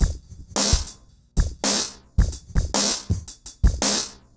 RemixedDrums_110BPM_05.wav